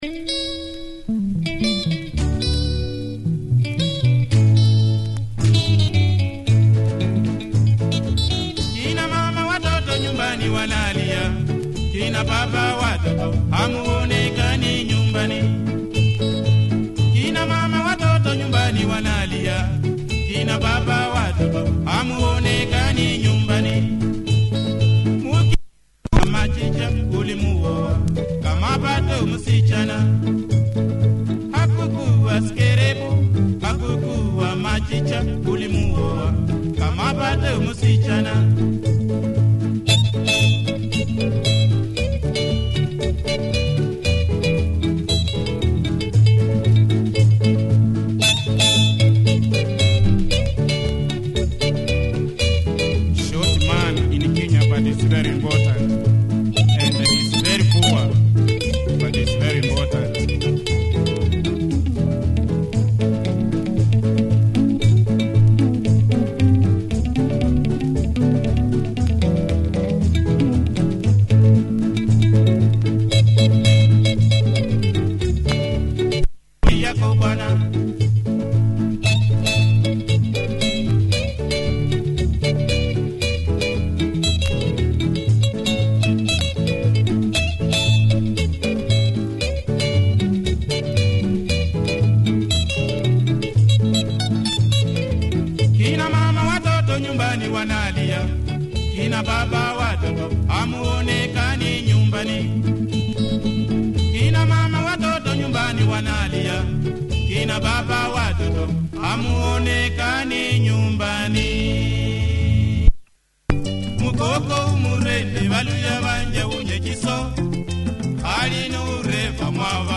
good guitar picking and good shuffle beat